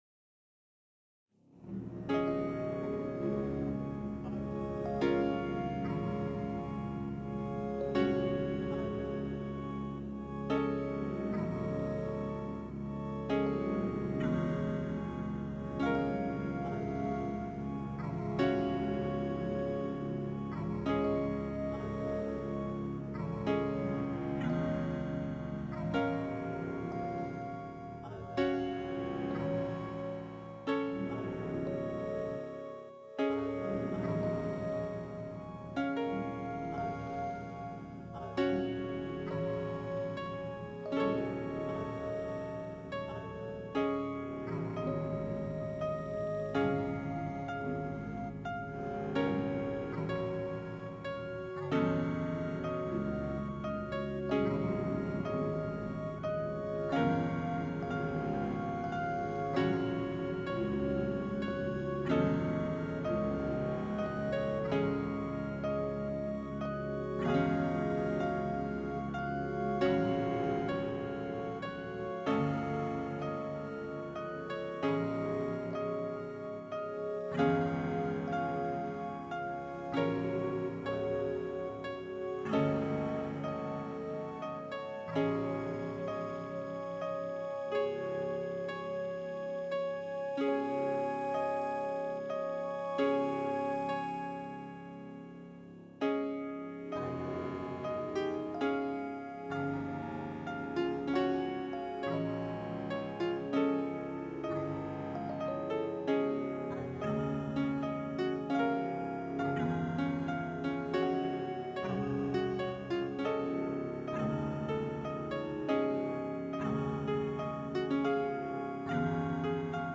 Peaceful tune, but drags on a looong ime.